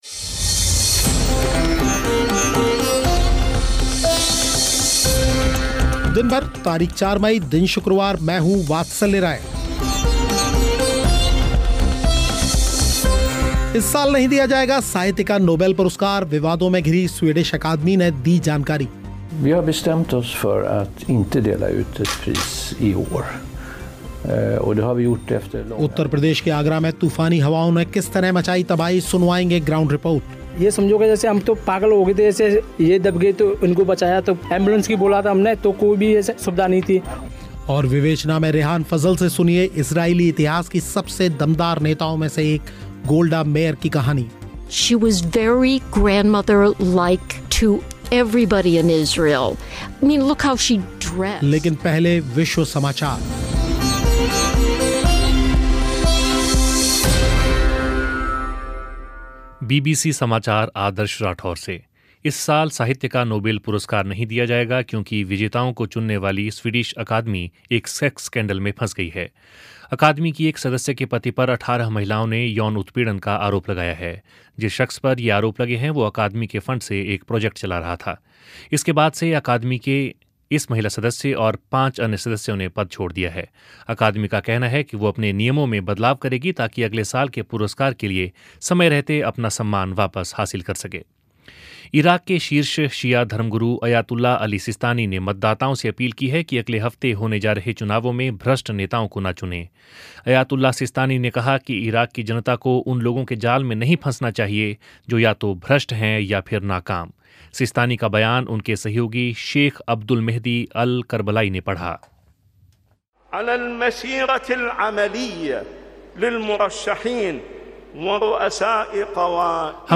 साथ में श्रोताओं ने भी रखी अपनी राय